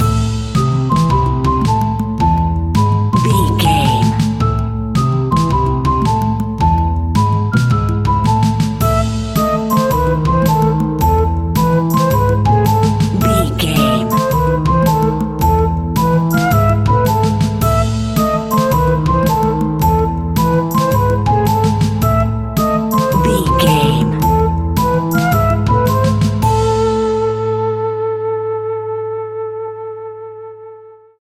Aeolian/Minor
scary
ominous
eerie
playful
double bass
piano
drums
brass
spooky
horror music